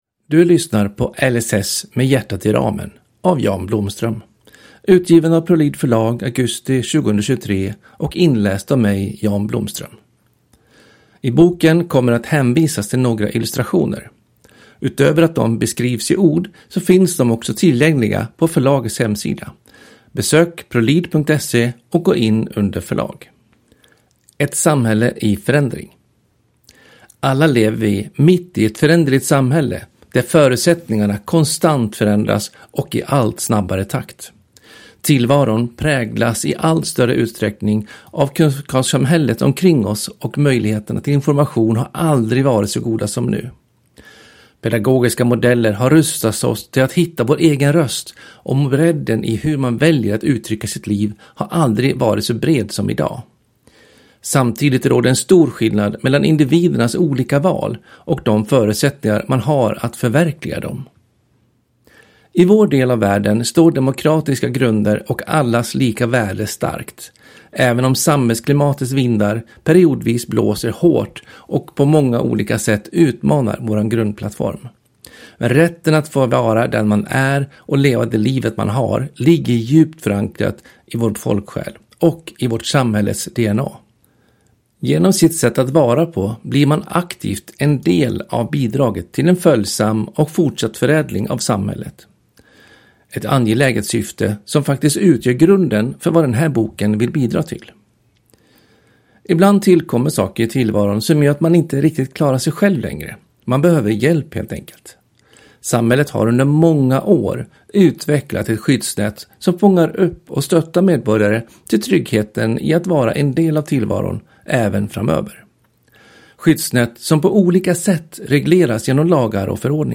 LSS - med hjärtat i ramen – Ljudbok – Laddas ner